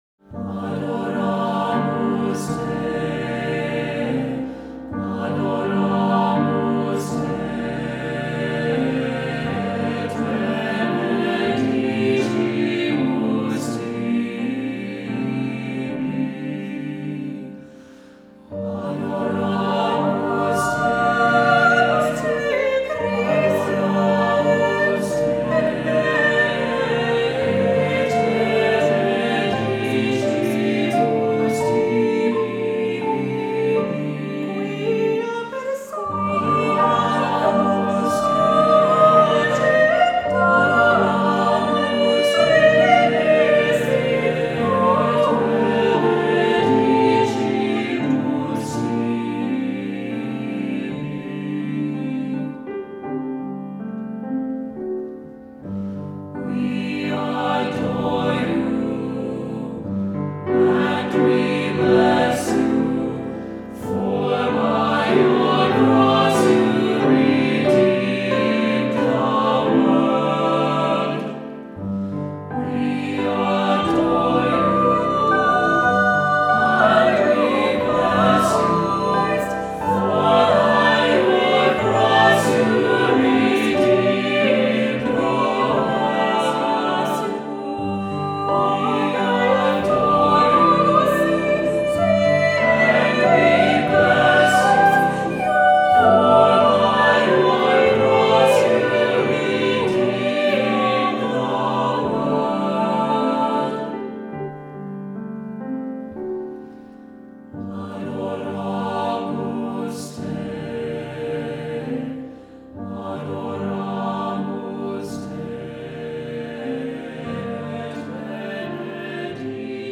Voicing: Cantor,SATB,Soloist or Soloists,Assembly